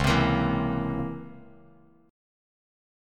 C#mM13 chord